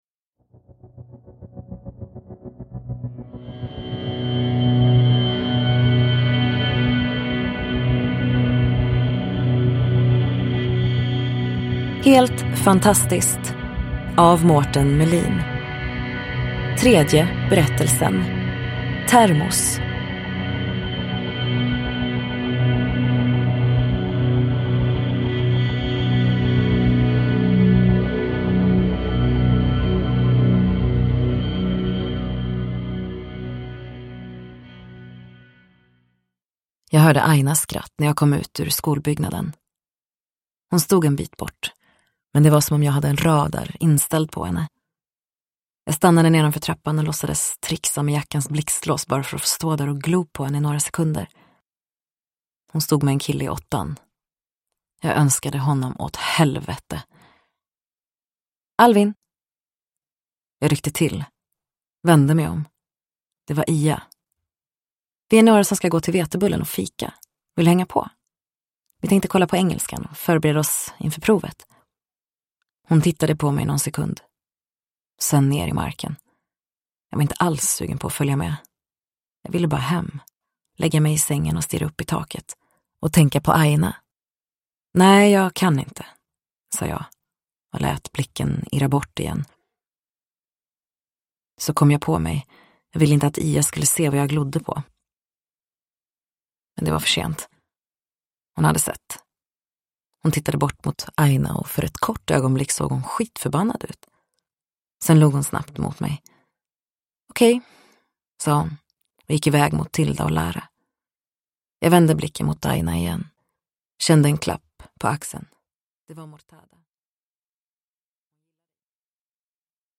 Termos : en novell ur samlingen Helt fantastiskt – Ljudbok – Laddas ner